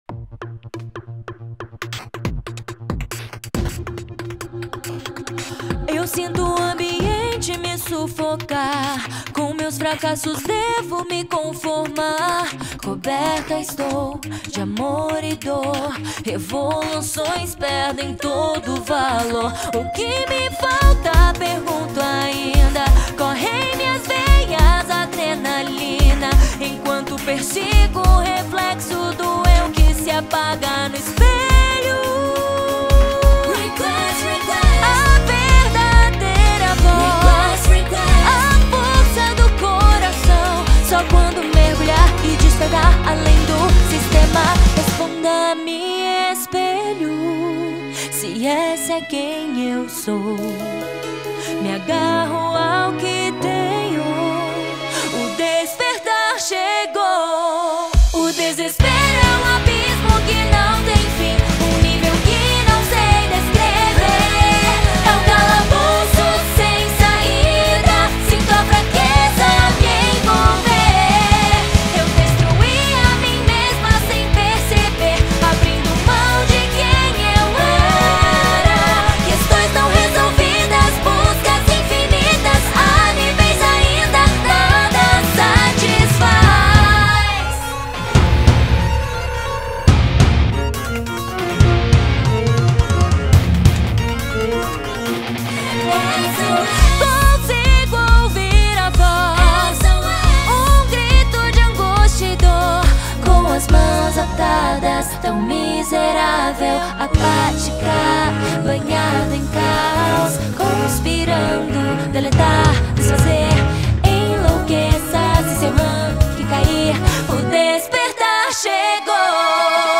2025-02-23 16:13:17 Gênero: Rap Views